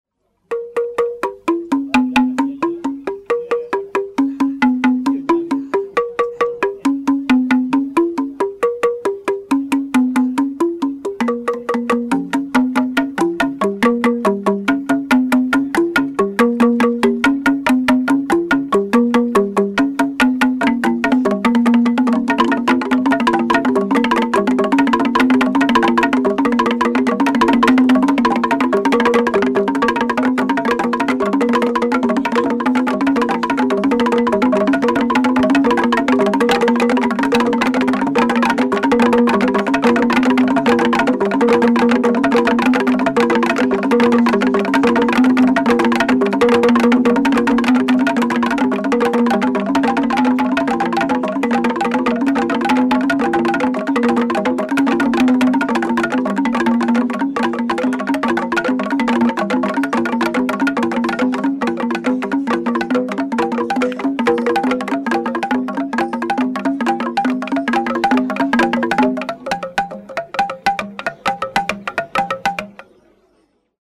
Folk music
Field recordings
sound recording-musical
This set of xylophone notes is played by five men, two on the one side and there on the other side of the notes which lie on their banana supports between them. The players beat the ends of the notes, not their centres, and if they slip out of place they are pushed back by the players on either side. The notes are made of Isambya wood.
Kwabyalumbe dance tunes with Ndara 13 note xylophone.
96000Hz 24Bit Stereo